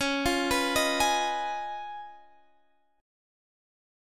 C#9 Chord
Listen to C#9 strummed